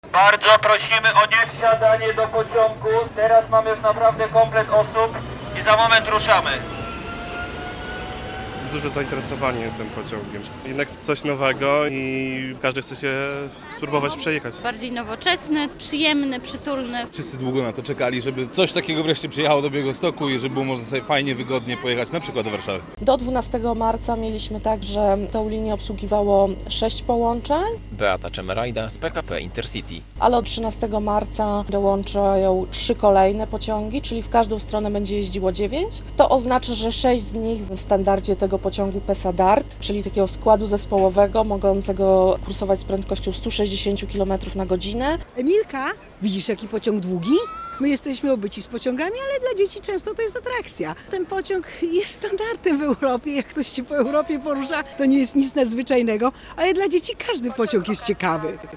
relacja
Takiego oblężenia białostocki dworzec PKP nie przeżywał od dawna. Setki osób przyszły w niedzielę (13.03) oglądać nowoczesny pociąg PesaDART.